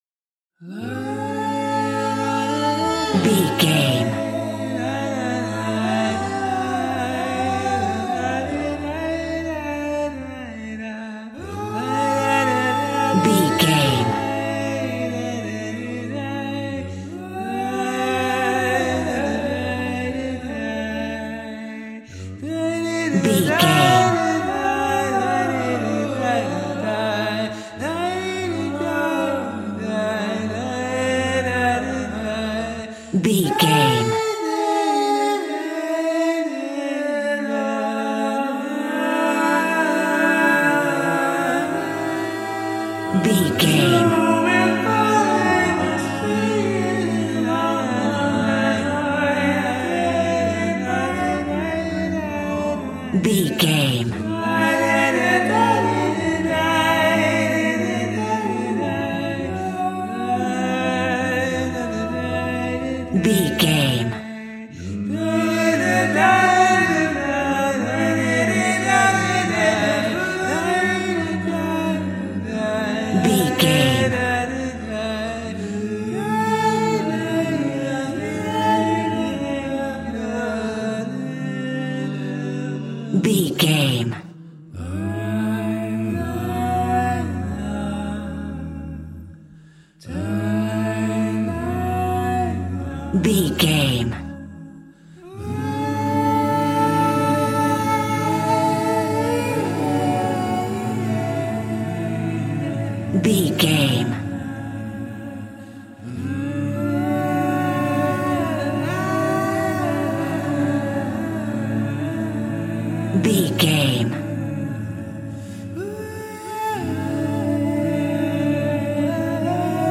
Ionian/Major
Slow
tranquil
synthesiser
drum machine